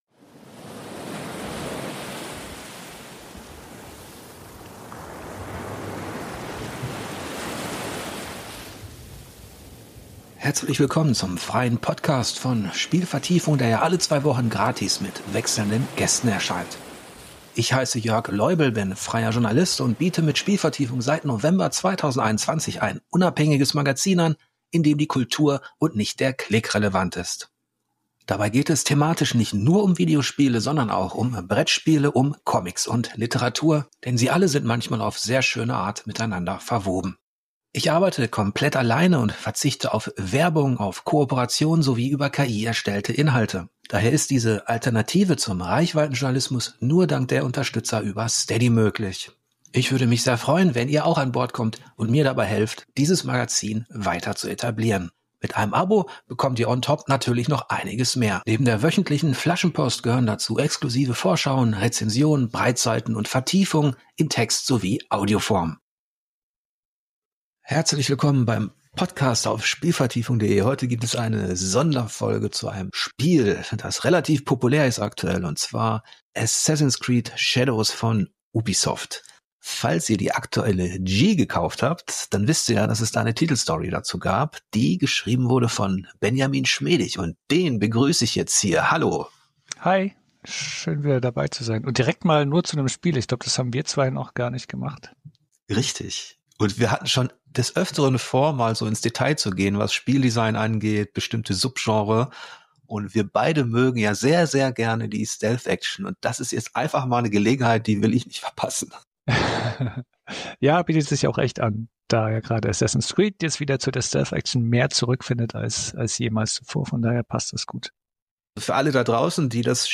Kostenloses Podcast-Format innerhalb von Spielvertiefung, das alle zwei Wochen meist ein Gespräch über Video- oder Brettspiele bis hin zu Game Studies anbietet.